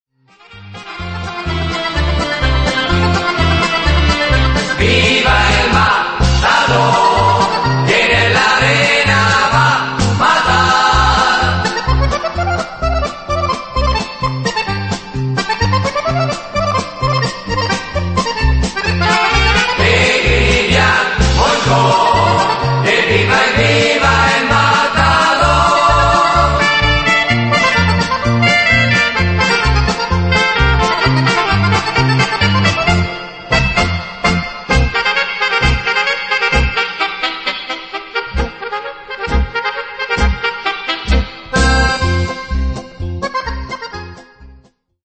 paso doble